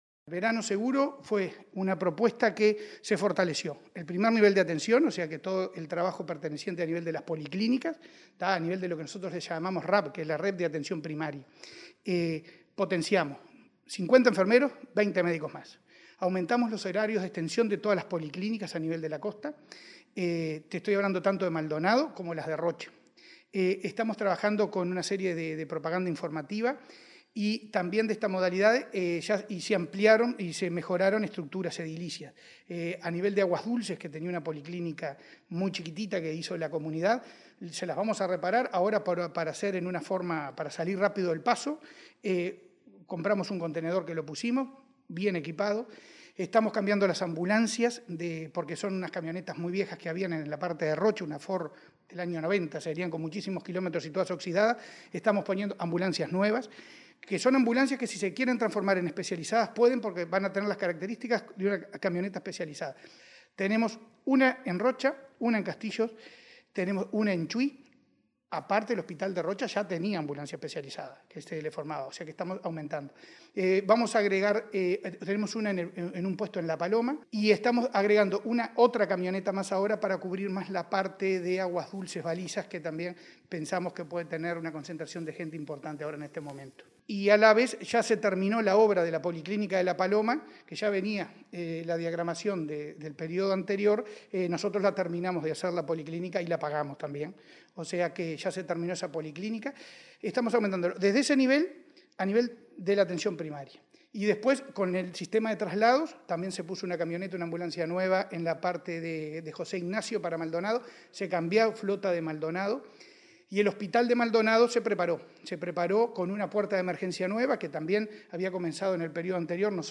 Cipriani en entrevista con Comunicación Presidencial sobre Verano Seguro